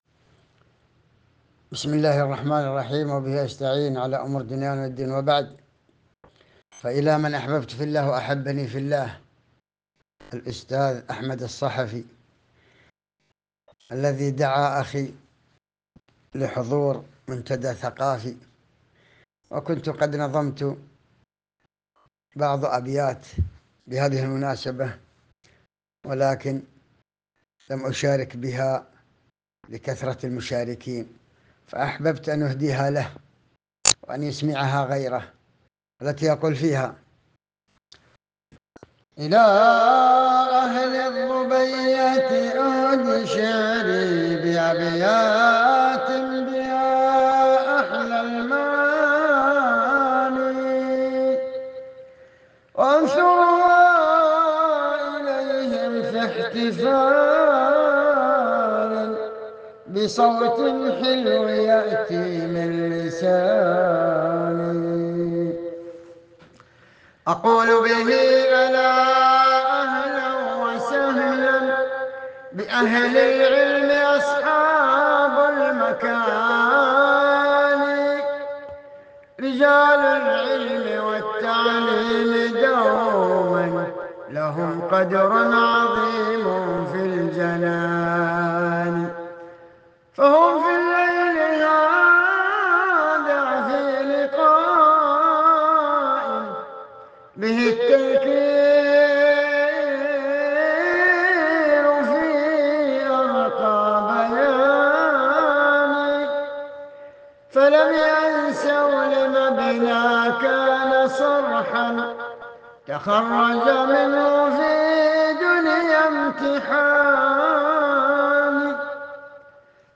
في الأمسية الثقافية بمحافظة خليص بمركز الظبية والجمعة…